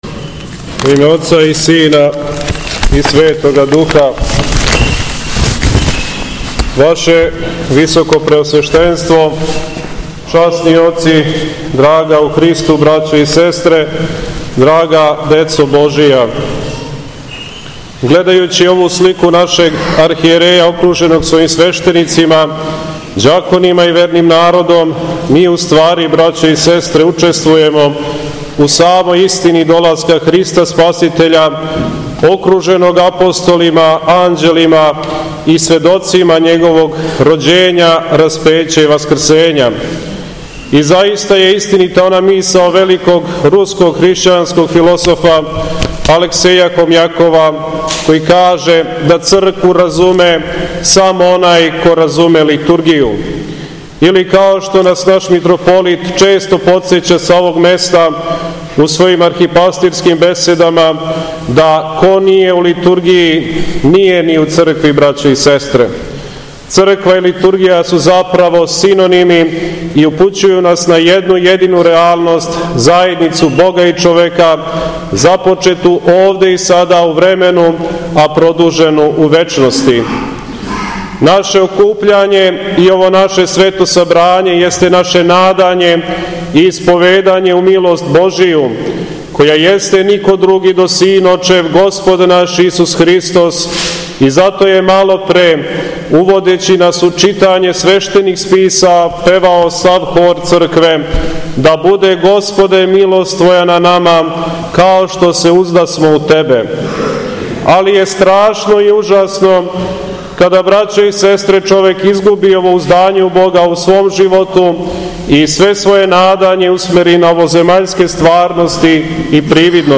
СВЕТА АРХИЈЕРЕЈСКА ЛИТУРГИЈА У ХРАМУ СВЕТОГА САВЕ У КРАГУЈЕВАЧКОМ НАСЕЉУ АЕРОДРОМ - Епархија Шумадијска
Беседа